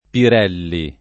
[ pir $ lli ]